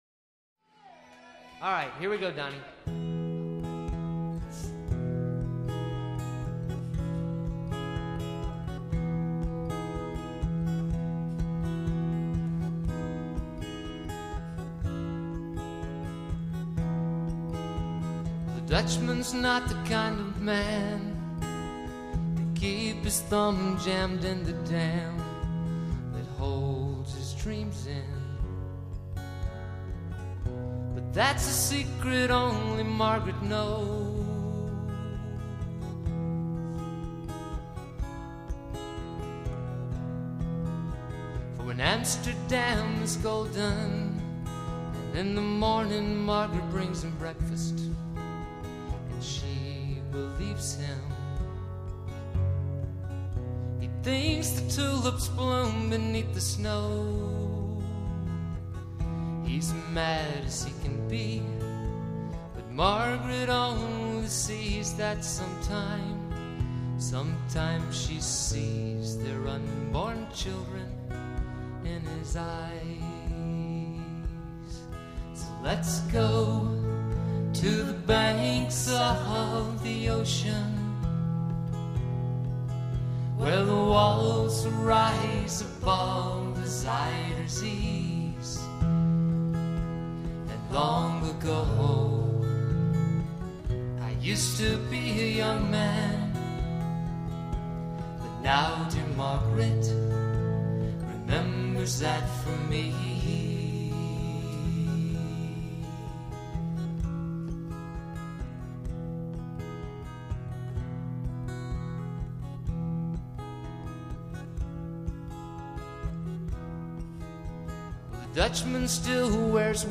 performed and recorded live